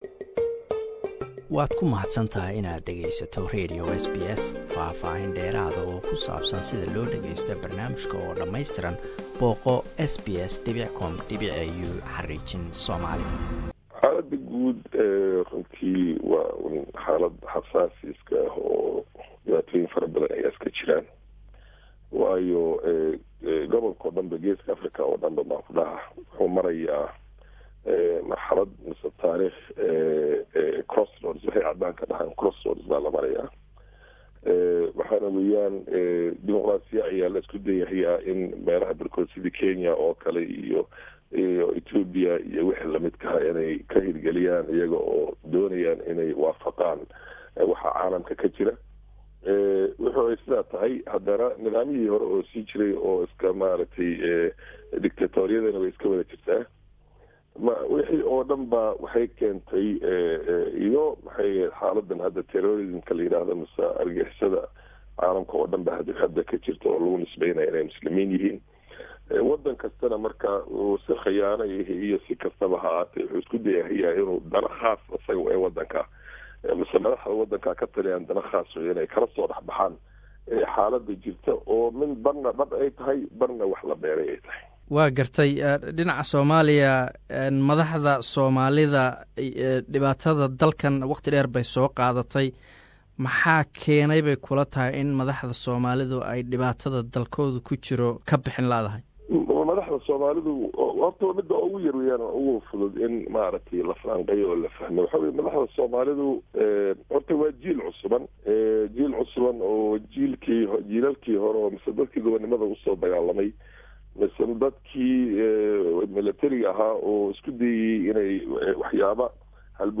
Interview with former deputy speaker of Kenyan Parliament, Farah Macalin. part 1